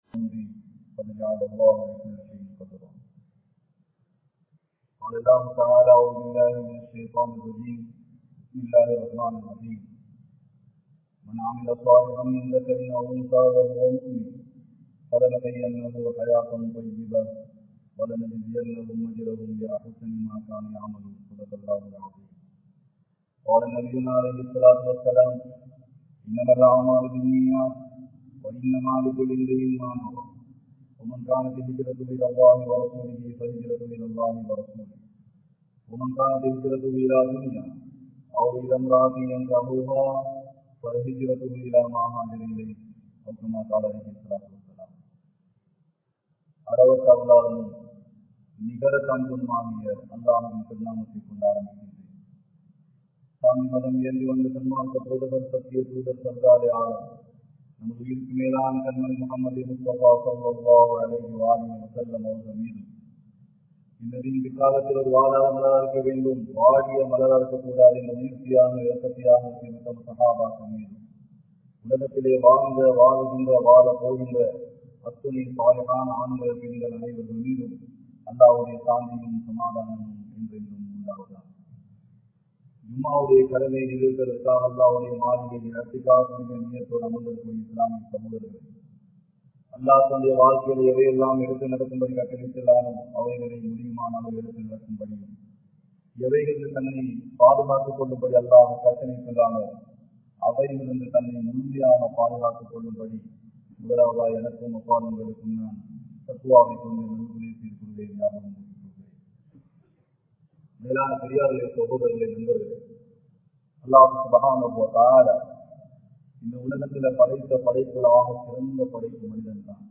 Allah Athisaya Padum Vaalifarhal (அல்லாஹ் அதிசயபடும் வாலிபர்கள்) | Audio Bayans | All Ceylon Muslim Youth Community | Addalaichenai